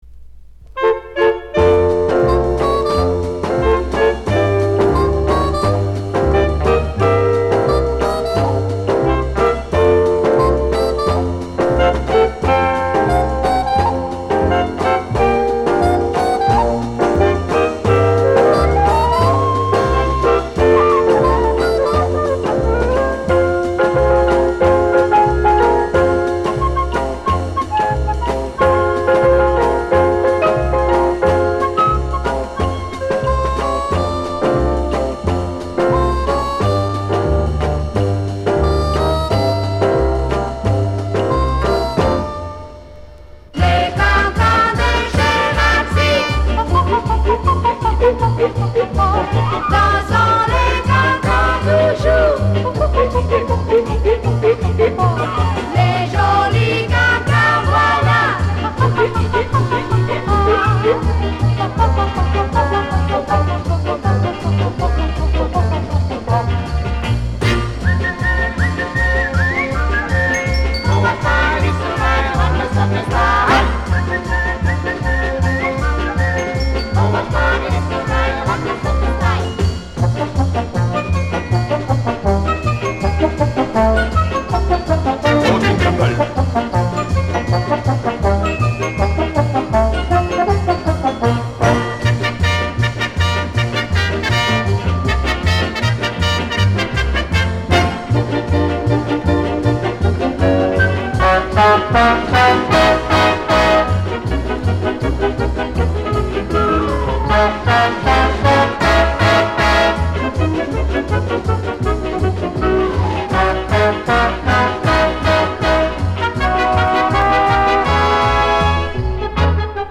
Оркестр
Реставрация с грампластинки.